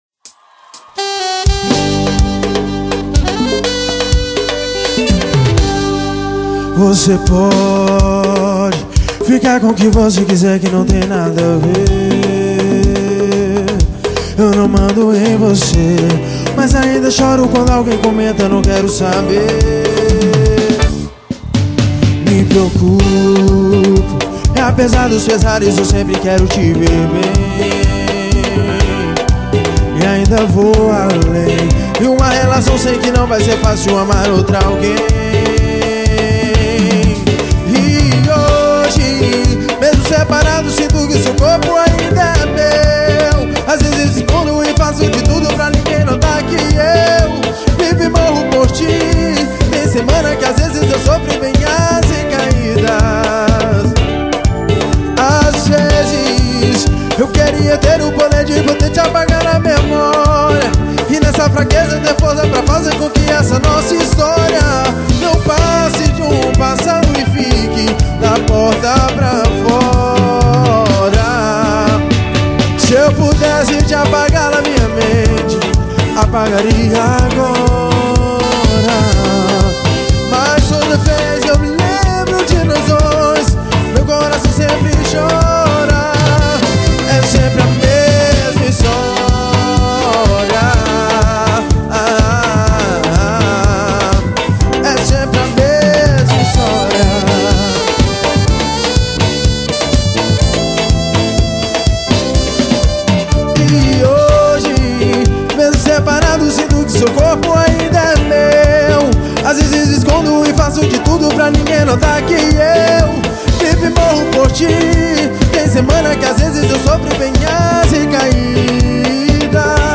Cd promocional ao vivo venda proibida.